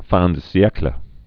(făɴdə-sē-ĕklə)